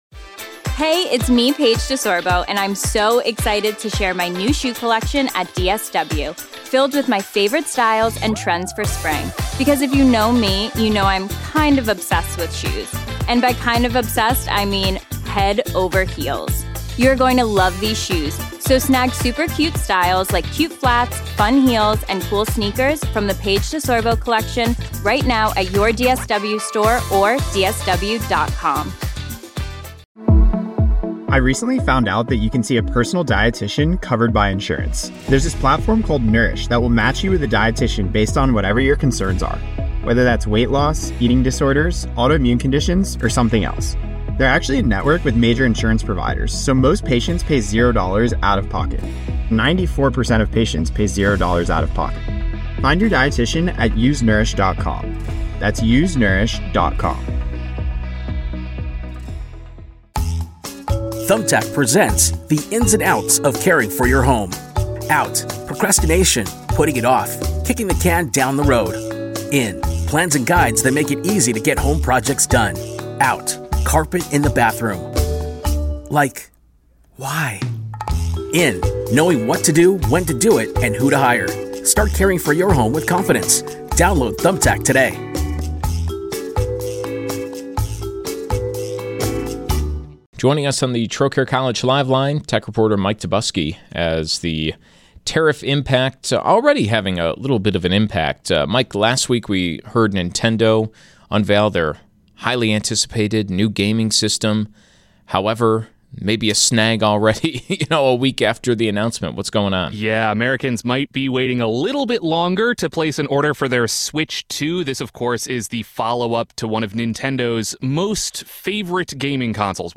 Collection of LIVE interviews from Buffalo's Early News on WBEN